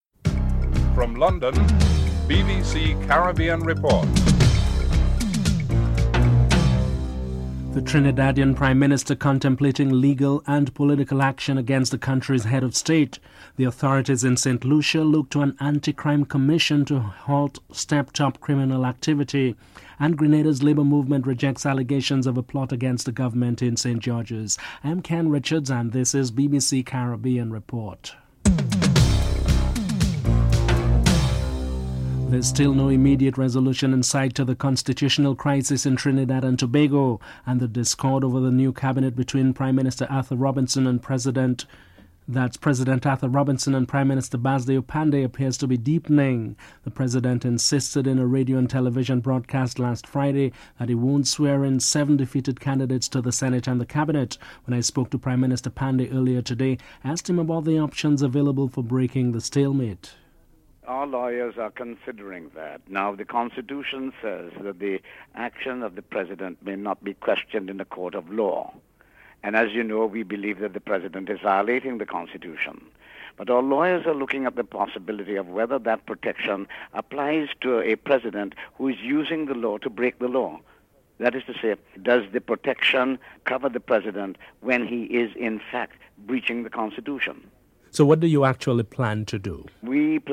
1. Headlines (00:00-00:31)
2. Trinidadian Prime Minister is contemplating legal and political action against the country's Head of State. Prime Minister Basdeo Panday is interviewed (00:32-03:51)
Prime Minister Pierre Charles is interviewed (10:53-12:00)